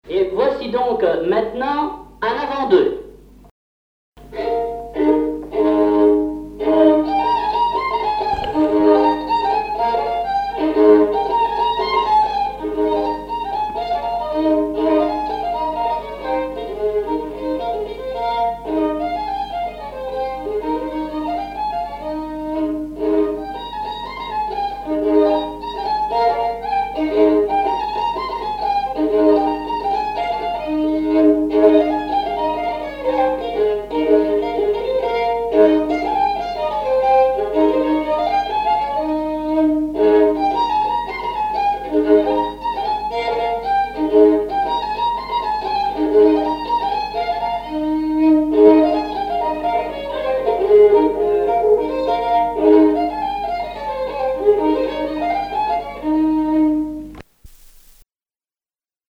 danse : branle : avant-deux
Répertoire du violoneux
Pièce musicale inédite